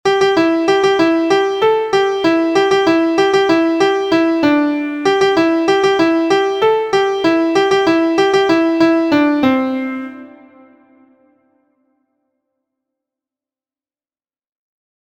• Origin: United States of America Play Song
• Key: C Major
• Time: 2/4
• Form: ABAC
• Pitches: beginners: So La Do Re Mi – pentatonic scale